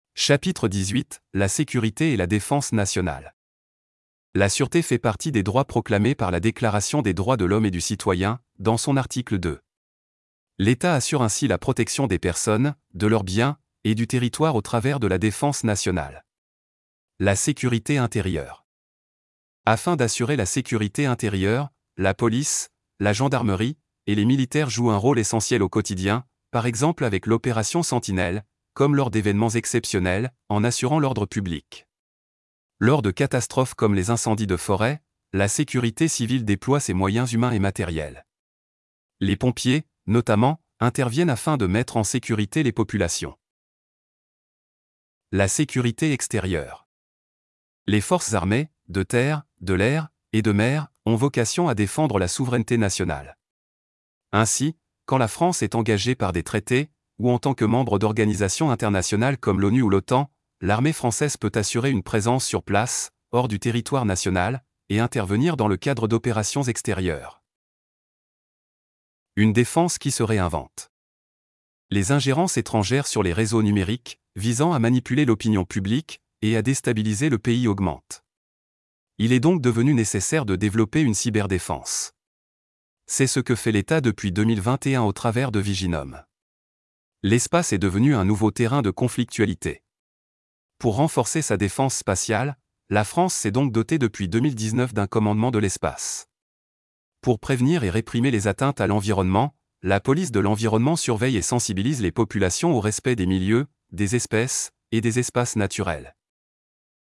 Chap. 18. Cours : La sécurité et la défense nationale